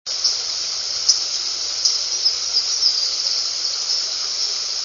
Louisiana Waterthrush
Phoebe, Chickadee, Titmice and Blue-Gray Gnatcatcher were also present.  Full song but much fainter with stream in background.